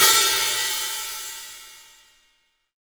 Q Hat op mx mf.WAV